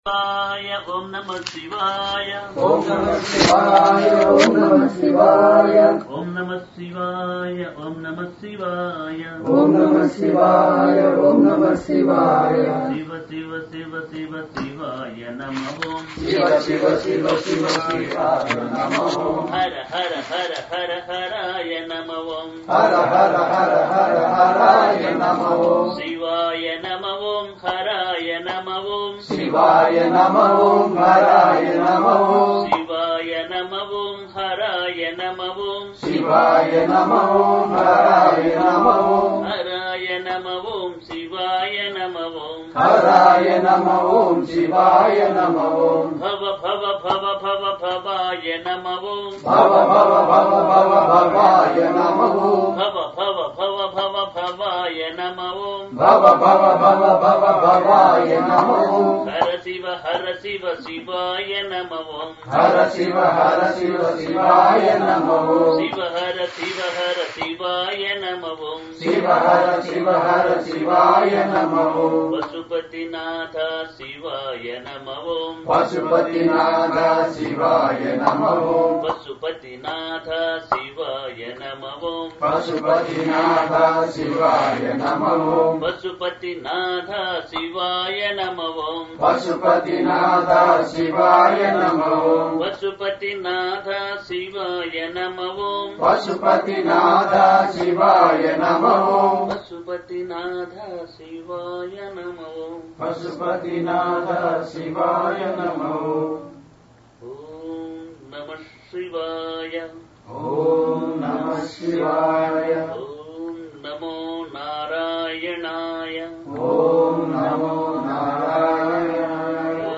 on the ocassion of Maha Sivarathri, February 24th 2017